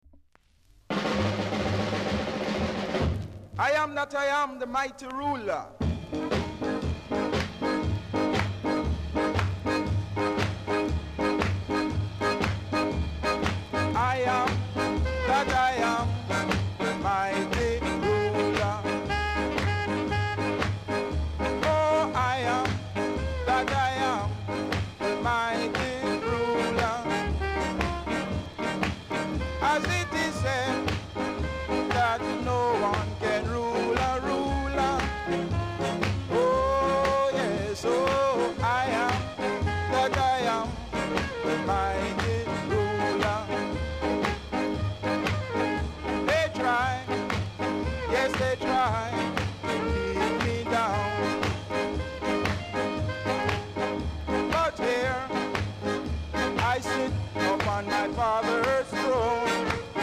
NICE SKA VOCAL!!